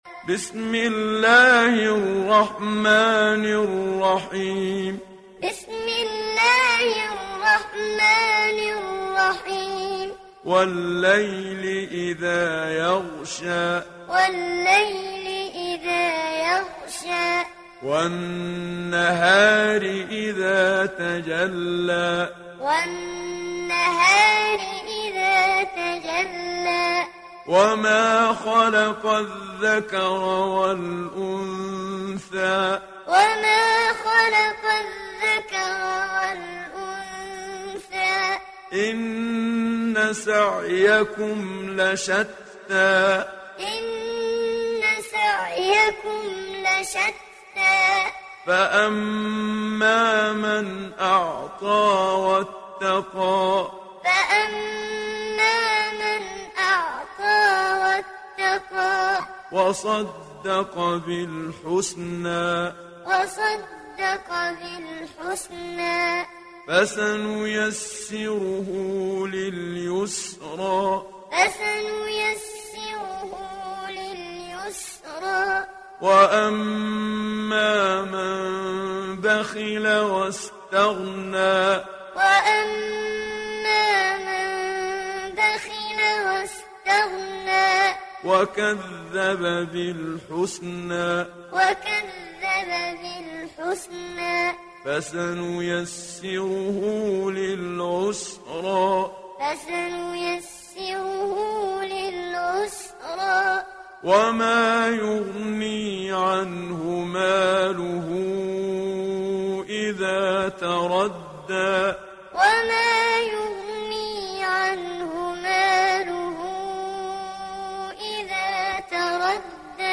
İndir Leyl Suresi Muhammad Siddiq Minshawi Muallim